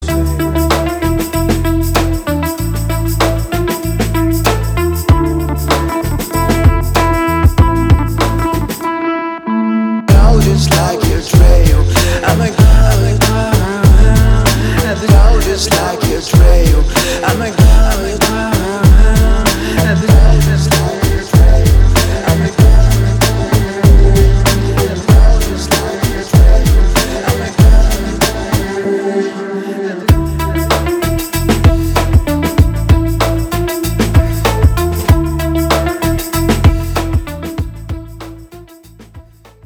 • Качество: 320, Stereo
атмосферные
красивый мужской голос
спокойные
Electronica
house